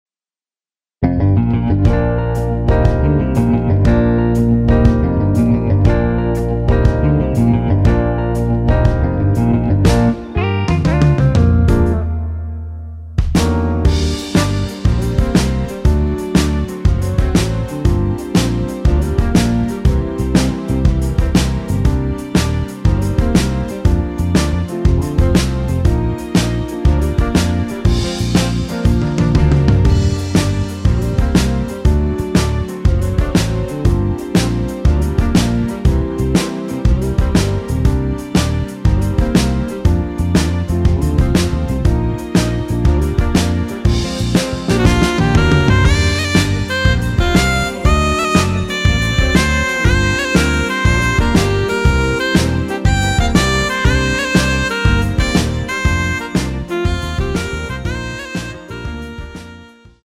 Bb
◈ 곡명 옆 (-1)은 반음 내림, (+1)은 반음 올림 입니다.
앞부분30초, 뒷부분30초씩 편집해서 올려 드리고 있습니다.